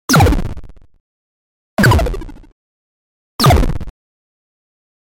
Звуки получения урона
Звуковые эффекты при получении урона в играх